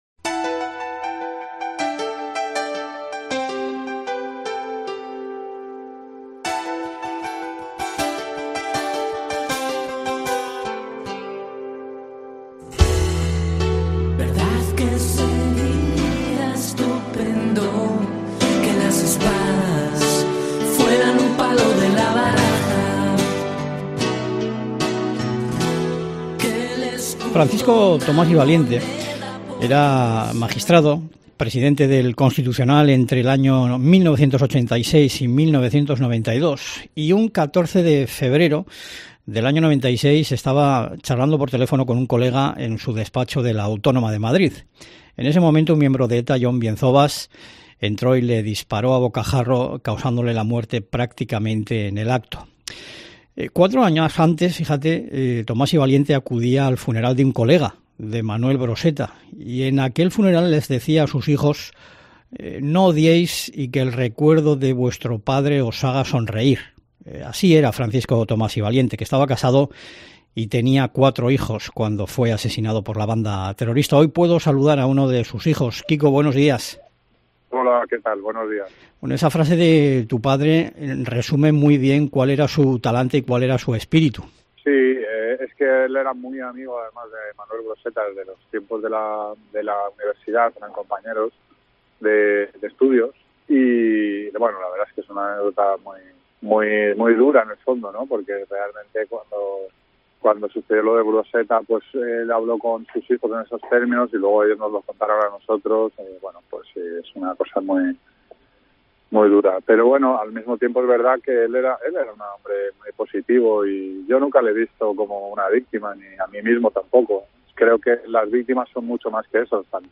en esta entrevista concedida a la cadena COPE